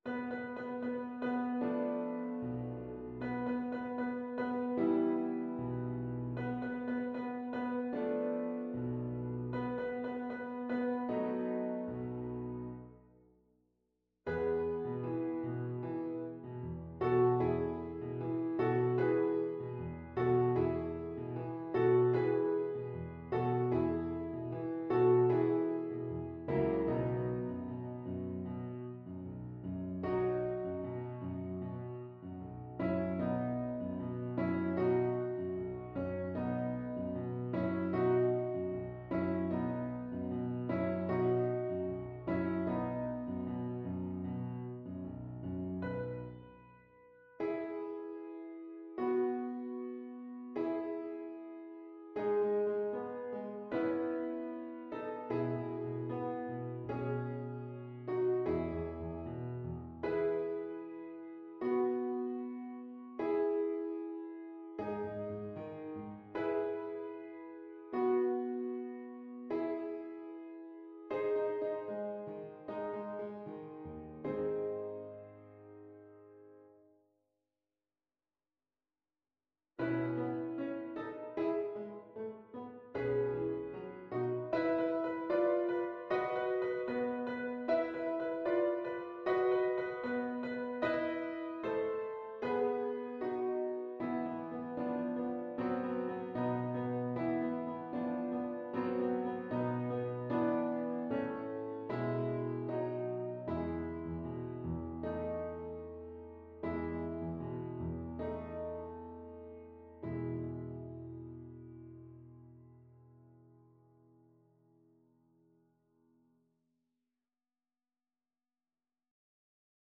E major (Sounding Pitch) (View more E major Music for Violin )
2/4 (View more 2/4 Music)
=76 Allegretto lusinghiero =104
Classical (View more Classical Violin Music)
saint_saens_havanaise_op83_VLC_kar1.mp3